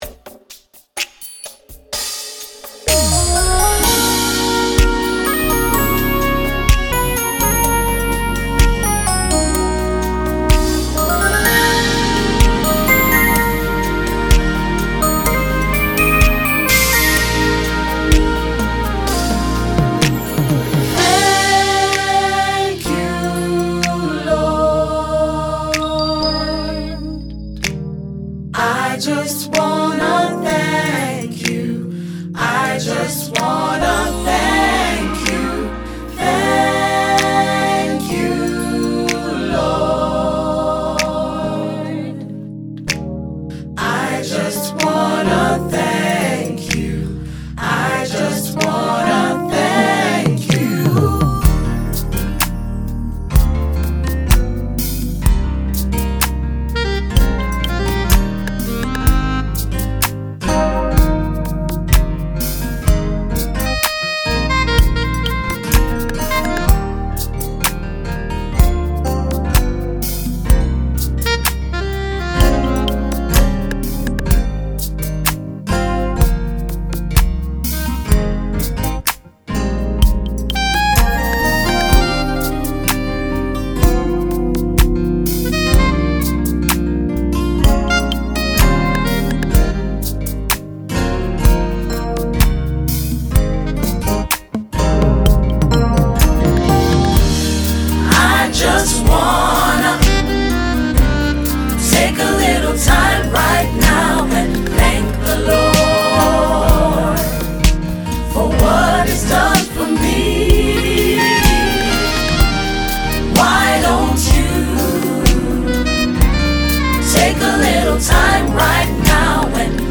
176 BPM